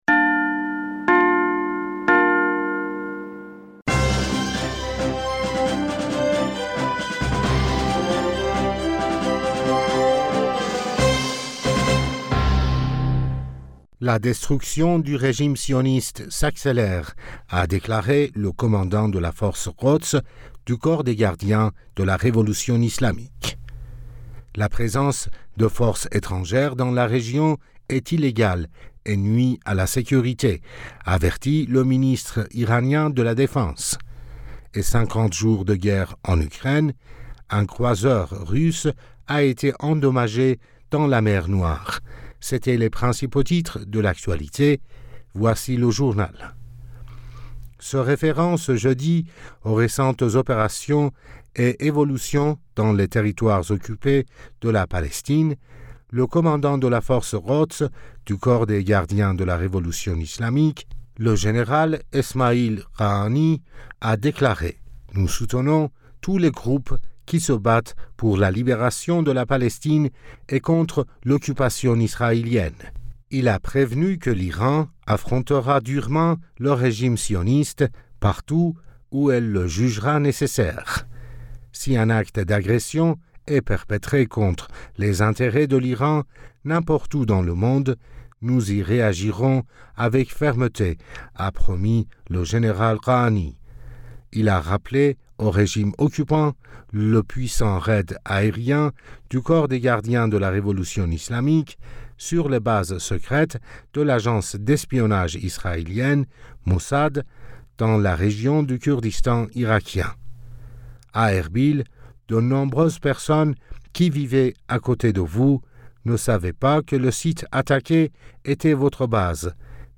Bulletin d'information Du 14 Avril 2022